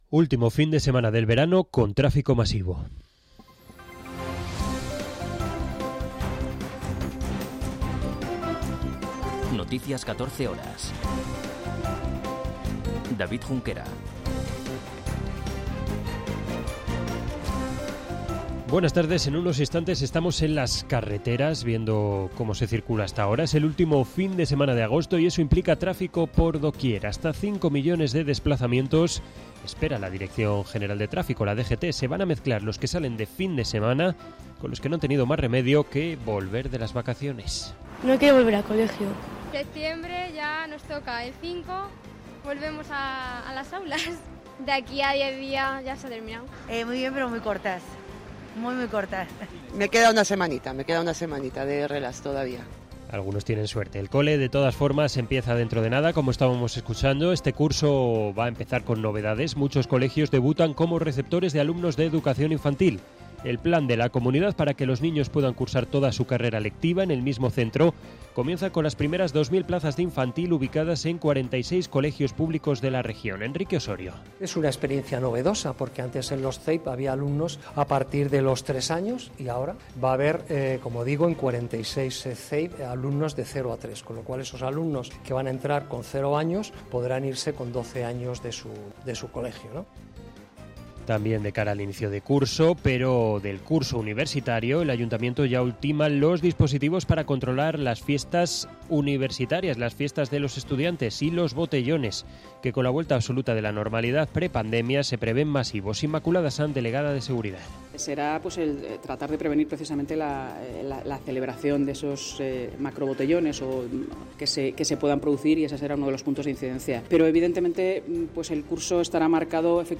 Noticias 14 horas 26.08.2022